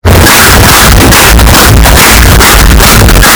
Bass Boosted Clapping